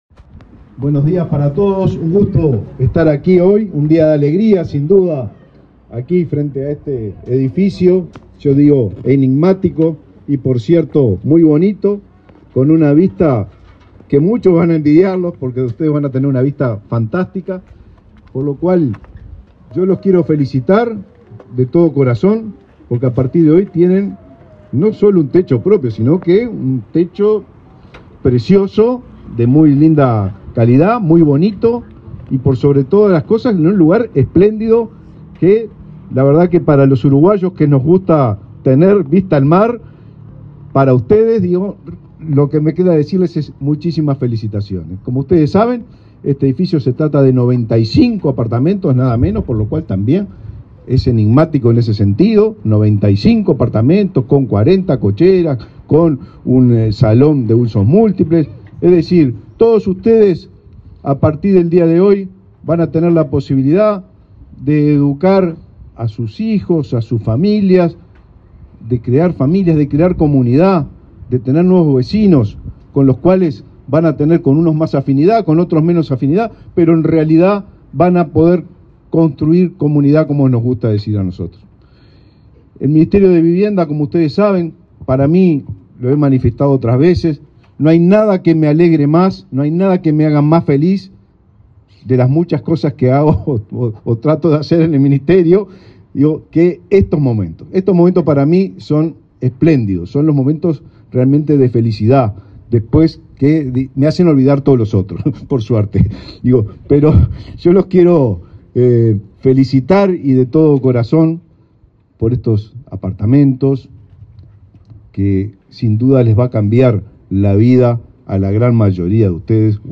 Palabras del ministro de Vivienda, Raúl Lozano
El ministro de Vivienda, Raúl Lozano, participó, este lunes 4 en Montevideo, de la inauguración de 95 viviendas del edificio gasómetro, en el centro